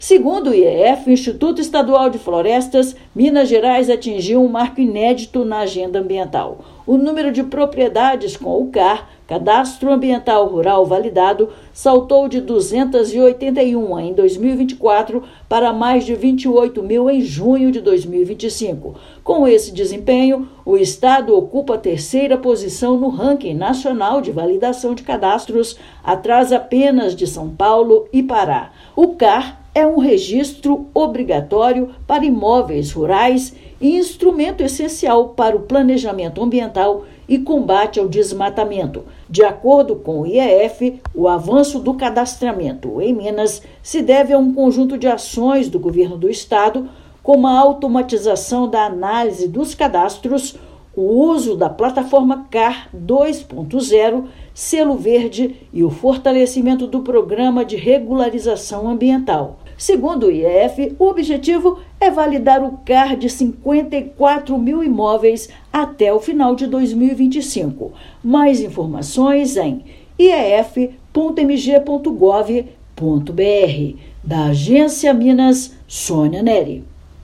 Estado alcança terceiro lugar nacional em validações; CAR é instrumento essencial para o planejamento ambiental, combate ao desmatamento e facilita acesso do produtor ao crédito. Ouça matéria de rádio.